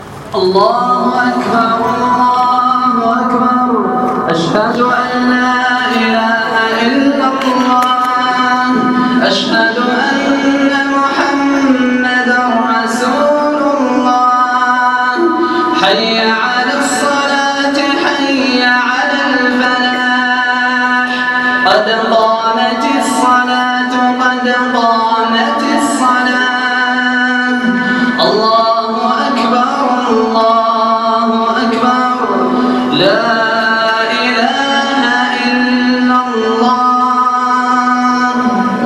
اقامة الصلاة بصوت جميل